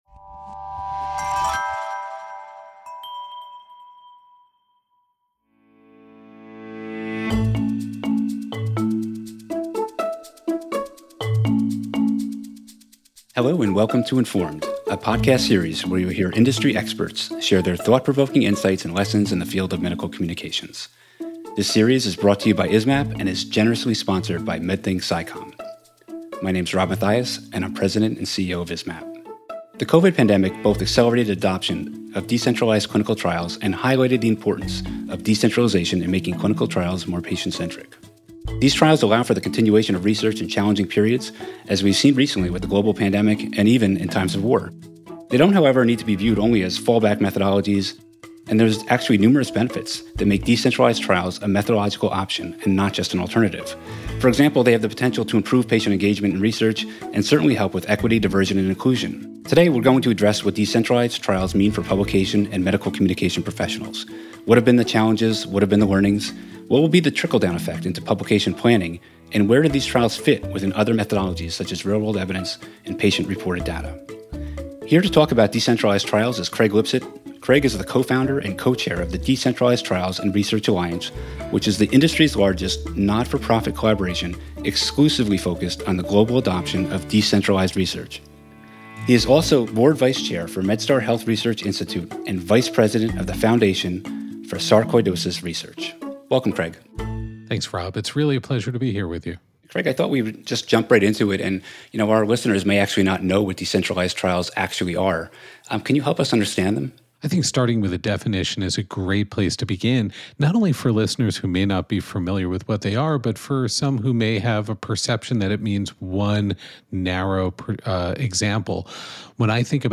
They have the potential to improve patient engagement in research and certainly help with equity, diversity and inclusion. Join us for a conversation about decentralized trials